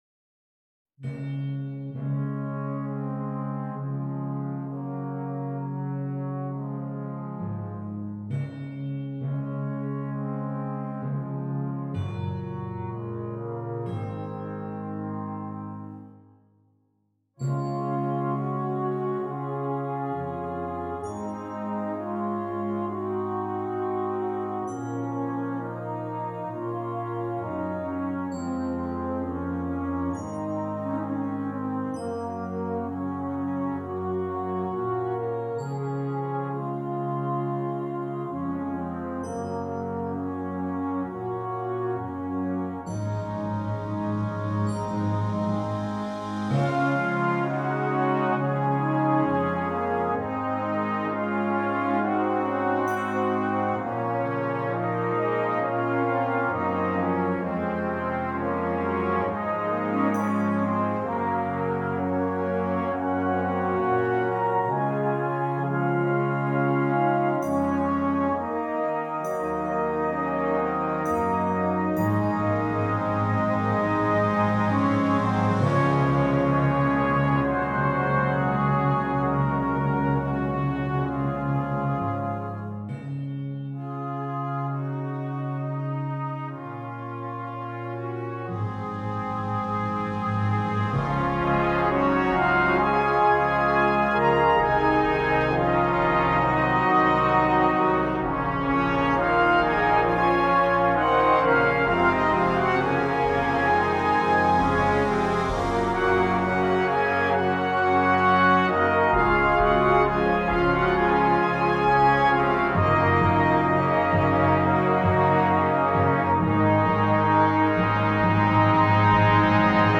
Brass Choir
is a calm, reflective piece